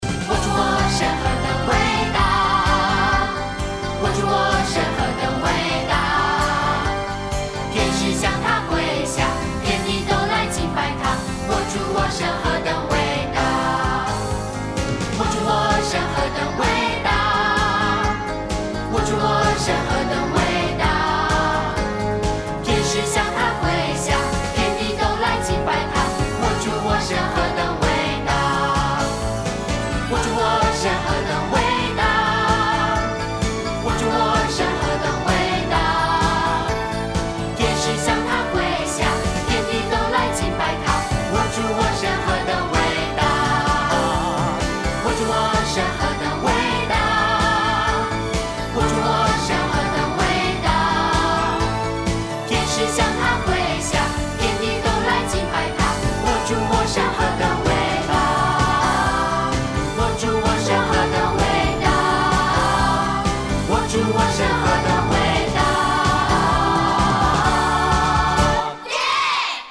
儿童赞美诗 | 我主我神何等伟大